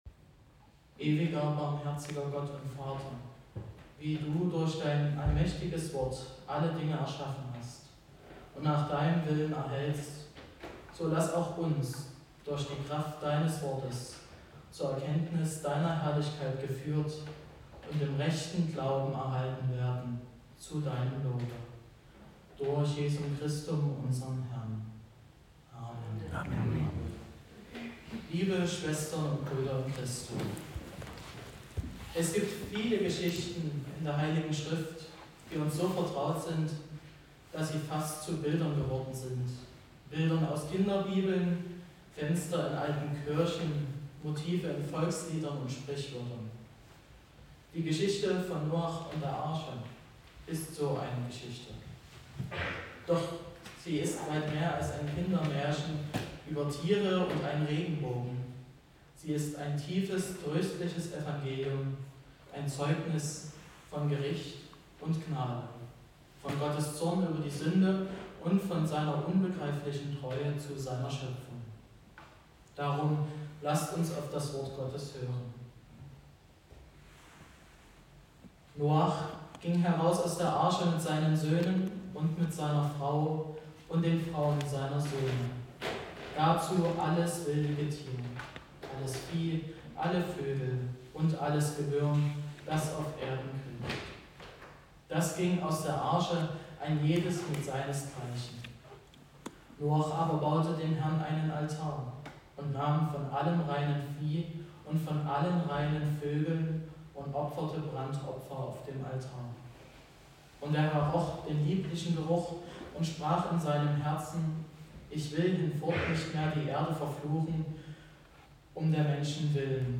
Gottesdienstart: Predigtgottesdienst Wildenau